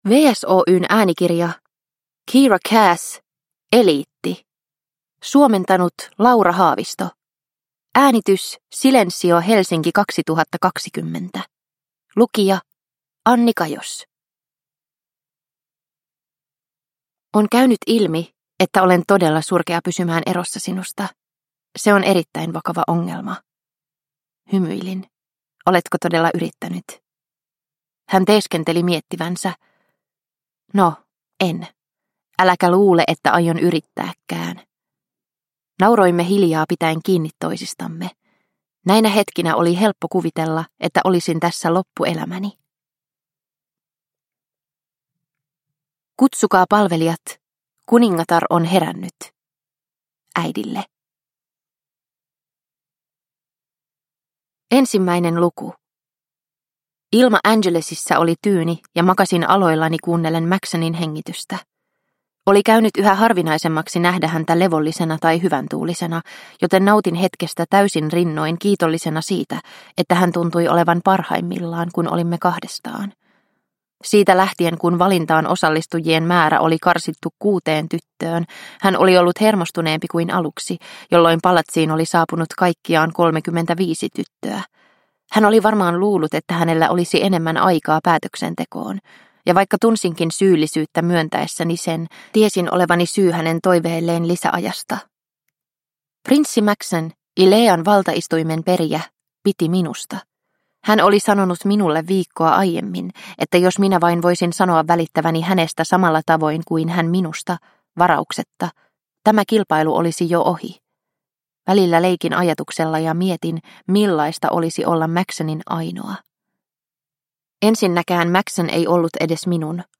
Eliitti – Ljudbok – Laddas ner